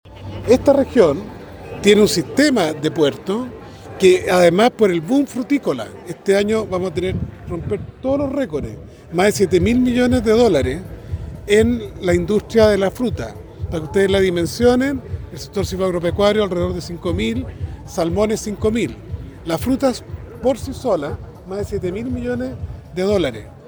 El ministro de agricultura, Esteban Valenzuela, fue consultado por la inquietud de los empresarios frutícolas frente al funcionamiento de Chancay en Perú.